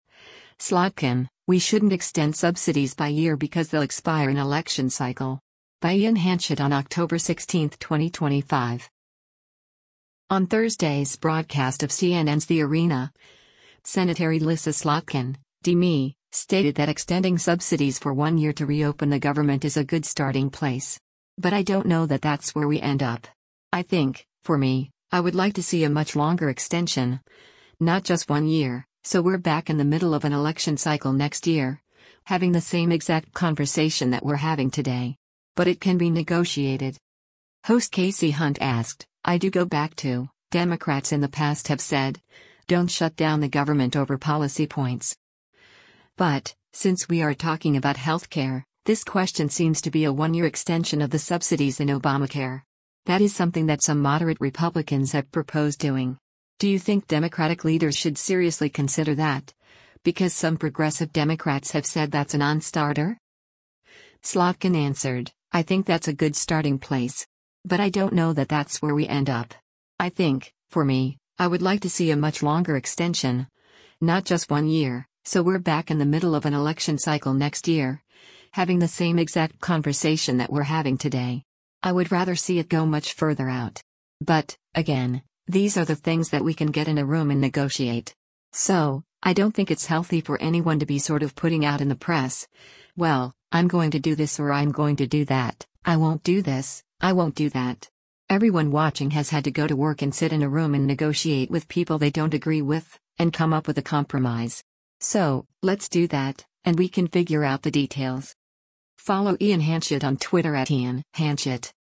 On Thursday’s broadcast of CNN’s “The Arena,” Sen. Elissa Slotkin (D-MI) stated that extending subsidies for one year to re-open the government is “a good starting place.